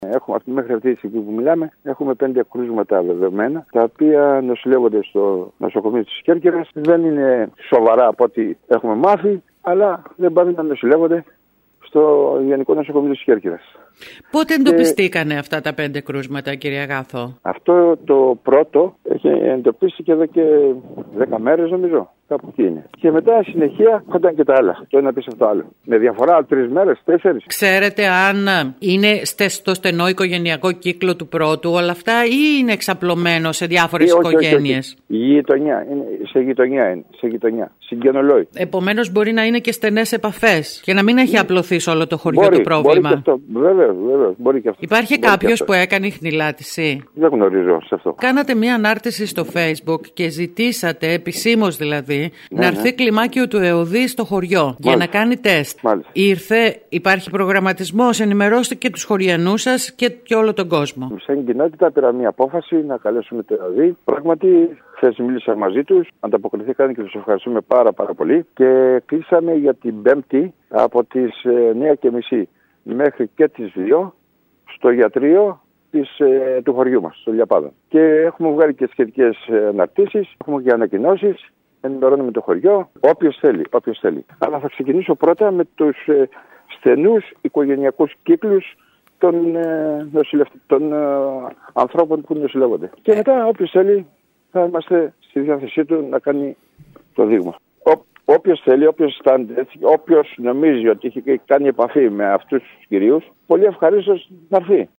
Ακούμε τον πρόεδρο του Τοπικού συμβουλίου, Γιάννη Αγάθο.